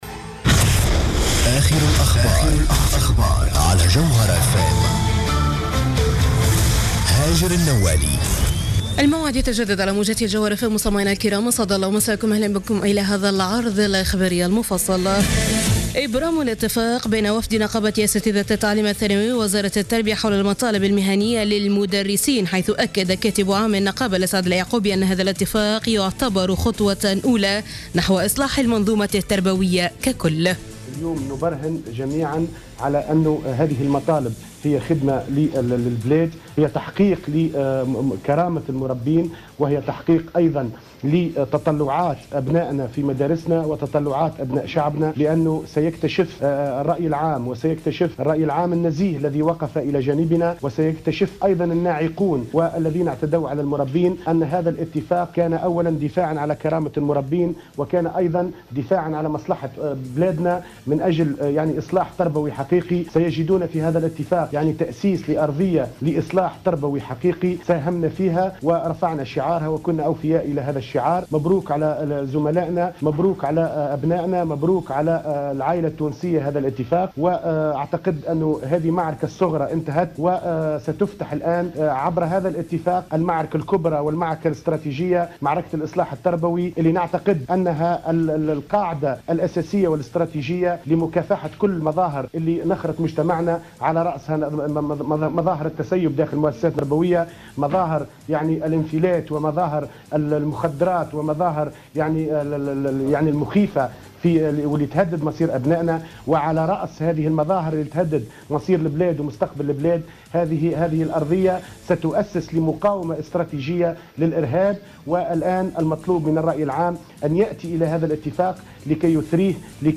نشرة أخبار منتصف الليل ليوم الثلاثاء 7 أفريل 2015